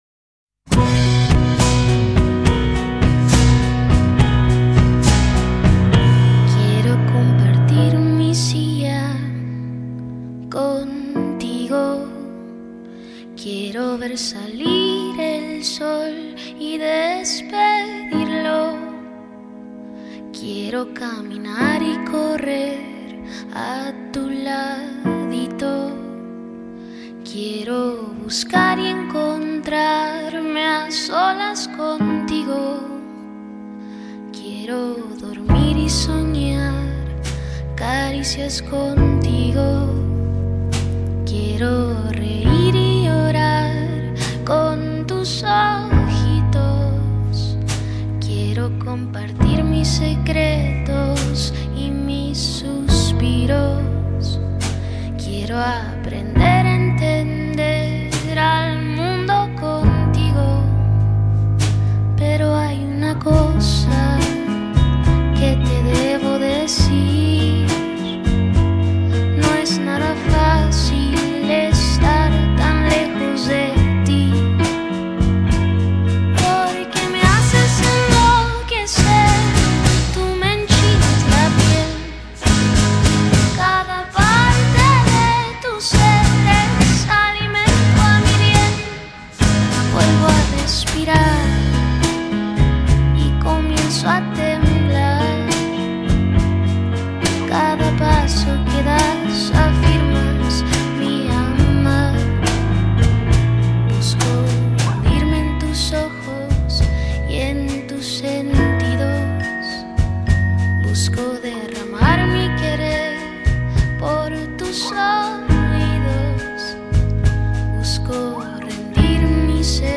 Un piano, guitarra, algunos loops y una voz llena de feeling